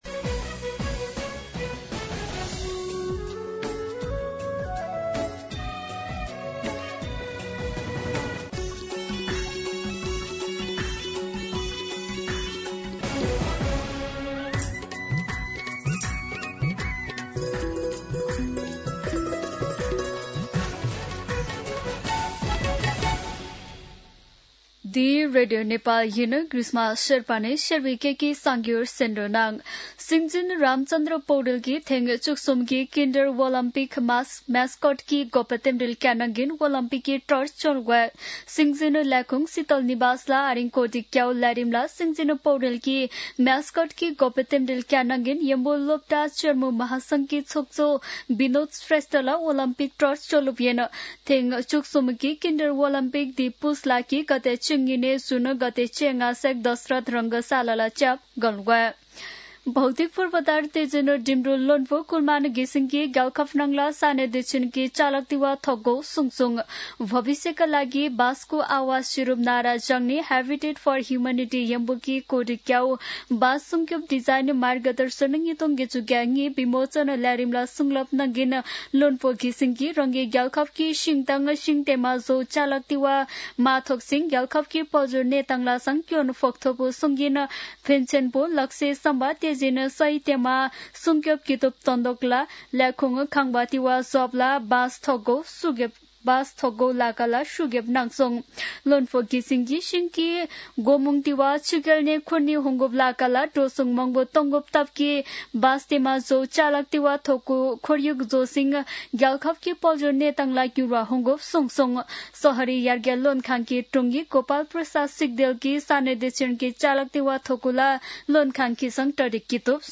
शेर्पा भाषाको समाचार : ४ पुष , २०८२
Sherpa-News-9-4.mp3